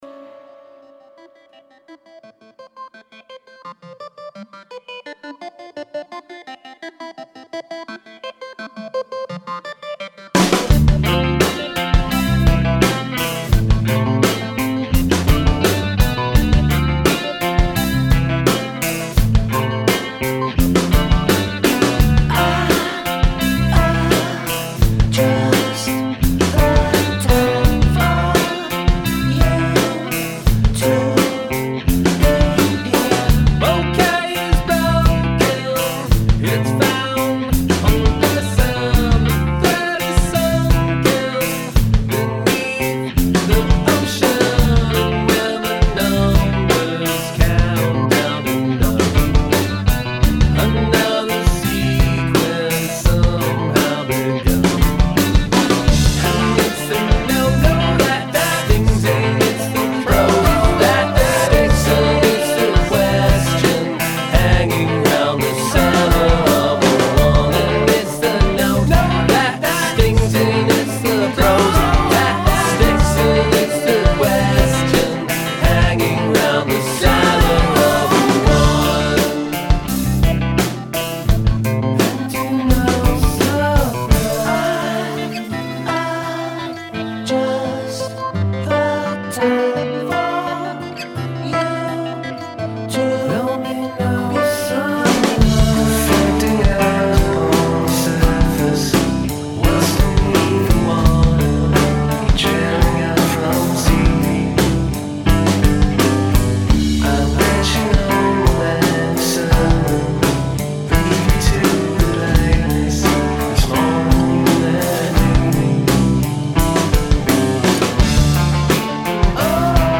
intricate, precise and articulate indie pop music